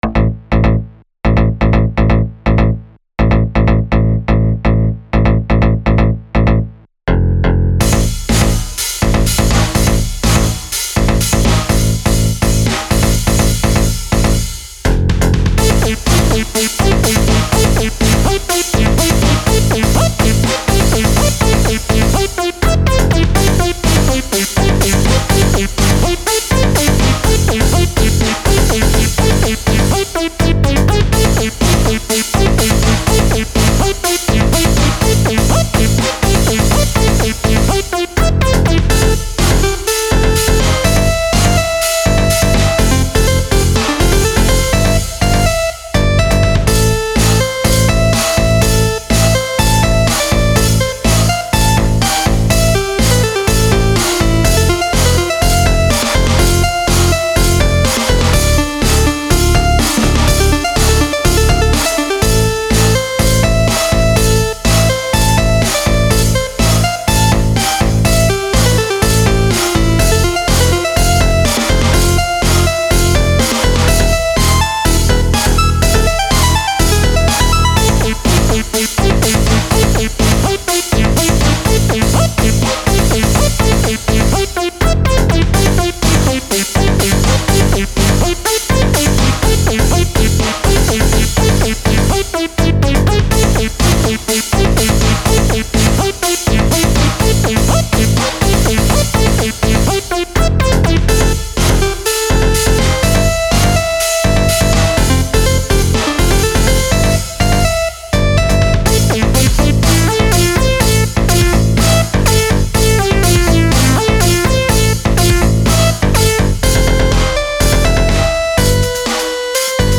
Tried to groove the bass line with the drums and provide some melodic movement with the other synths. A little bit of 8-bit mixed in taking turns with the lead part.